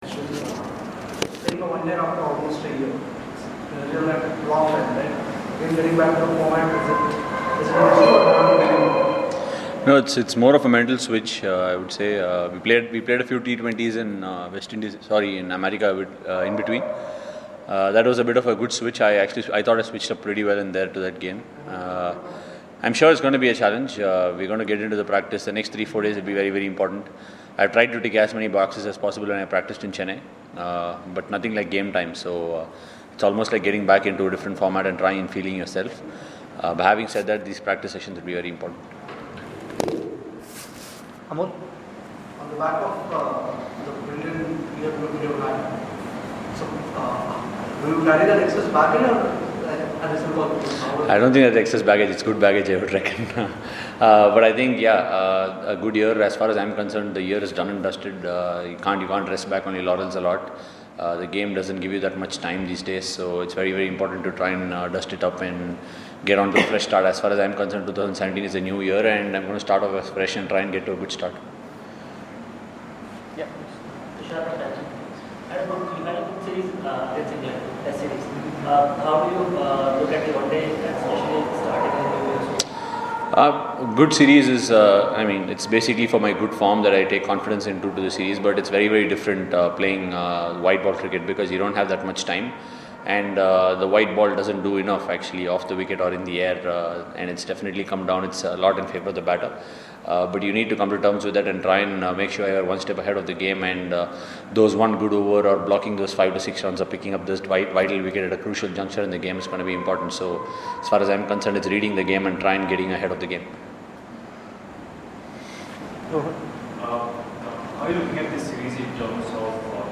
Listen: R Ashwin talks about preparations going into first ODI series under Virat Kohli